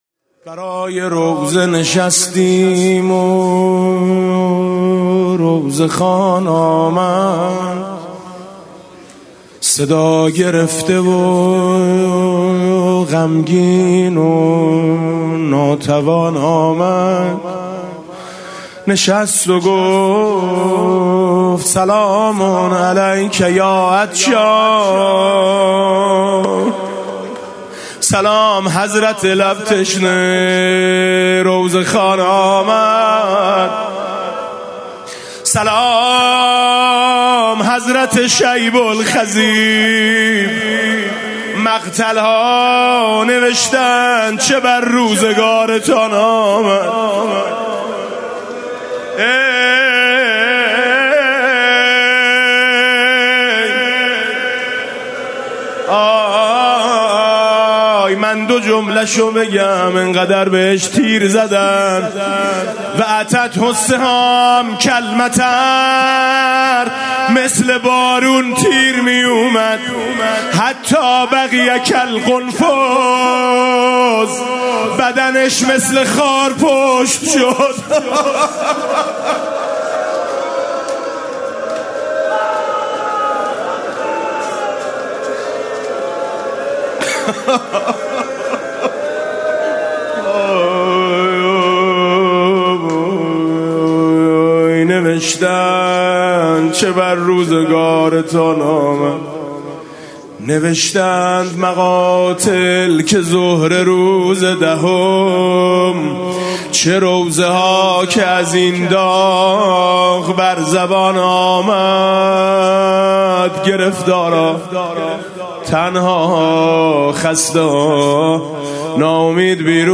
مسیر پیاده روی نجف تا کربلا [عمود ۹۰۹]
مناسبت: ایام پیاده روی اربعین حسینی
با نوای: حاج میثم مطیعی
حضرت لب تشنه (روضه)